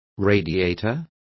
Complete with pronunciation of the translation of radiators.